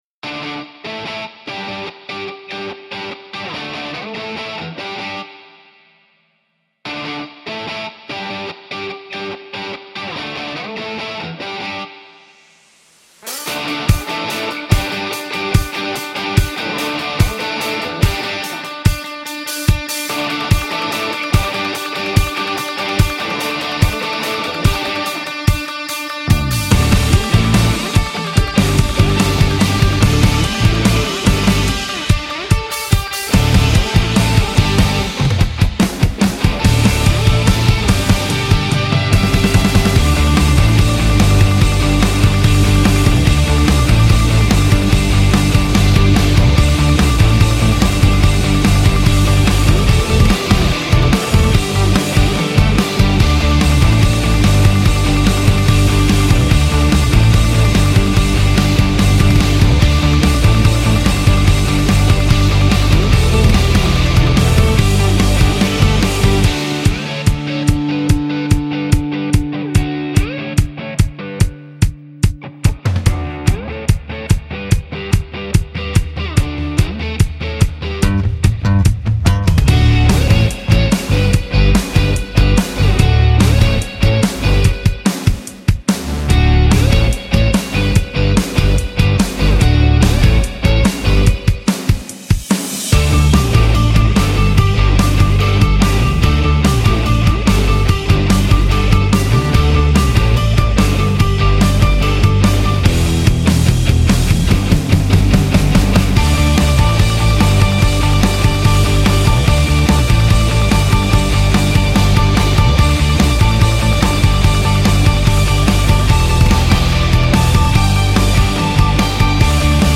Ритмичная